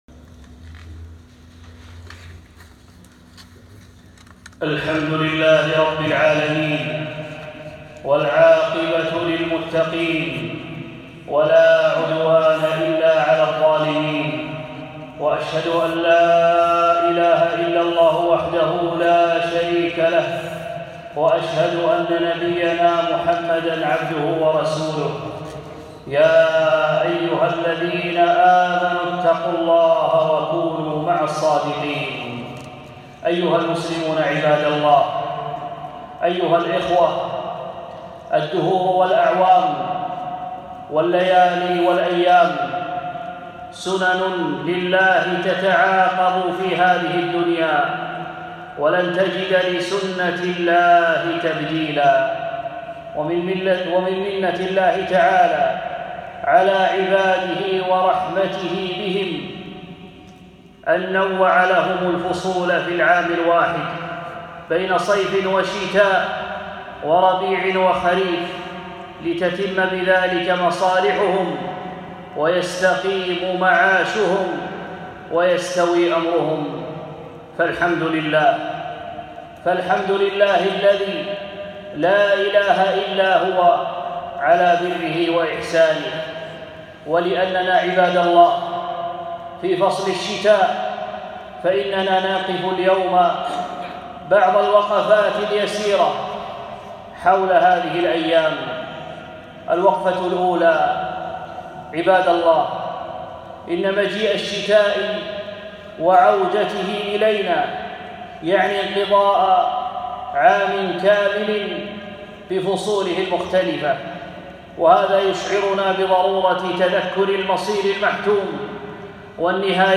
خطبة - وقفات مع الشتاء